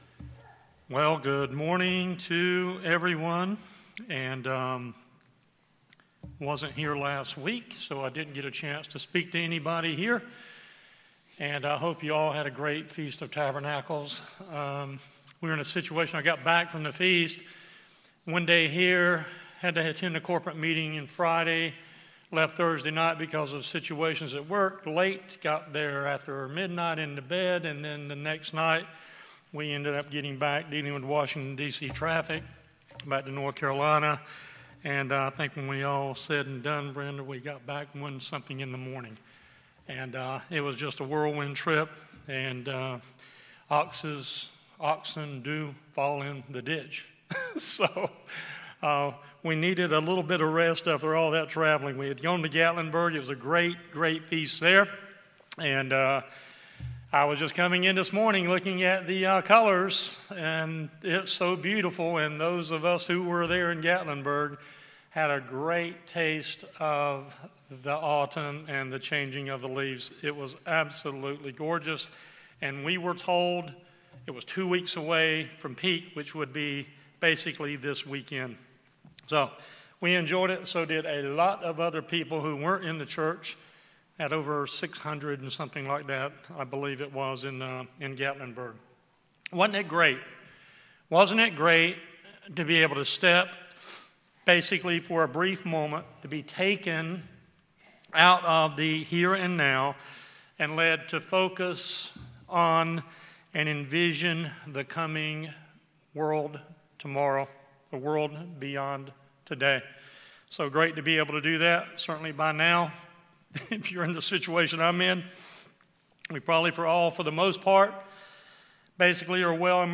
This sermon reviews six lessons JESUS teaches HIS Faithful Servants they need to be doing now in preparation for HIS return and entry into the coming Kingdom of GOD!
Given in Raleigh, NC